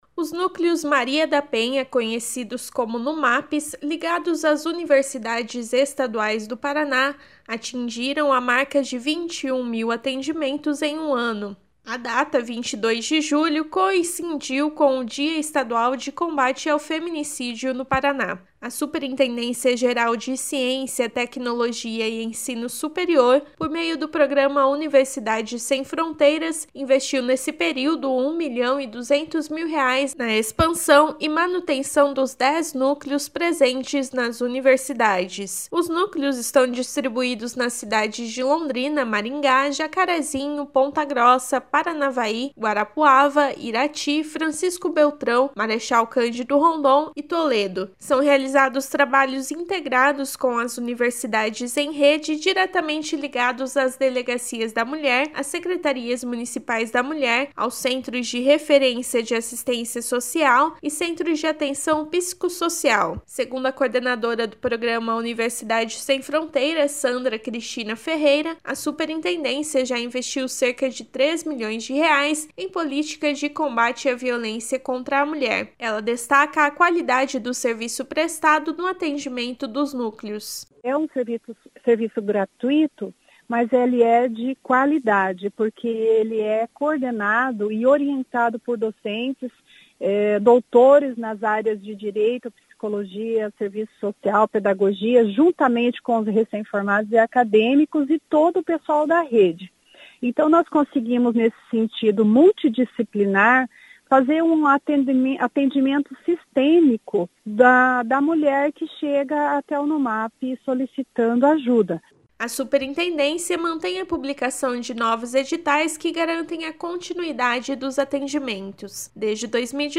Outras informações na programação da Rádio Cultura AM 930